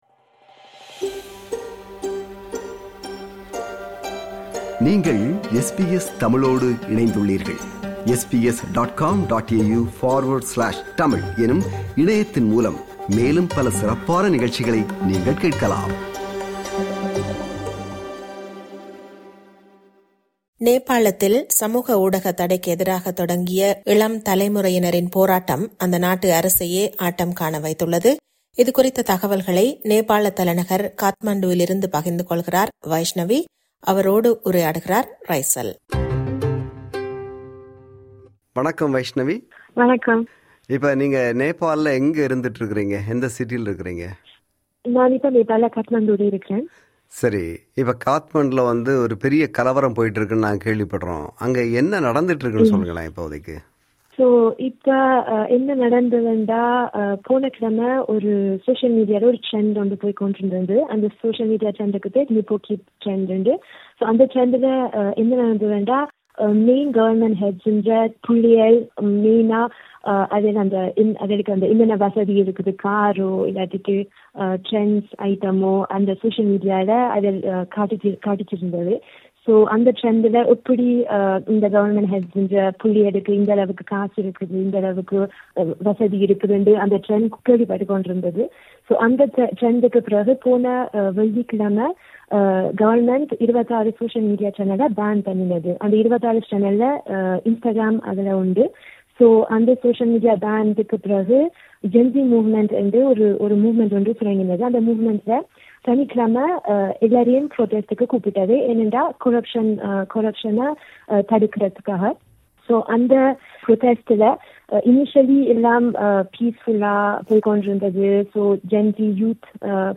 நேபாளத்தில் என்ன நடக்கிறது? அங்குள்ள தமிழ் மாணவி தரும் தகவல்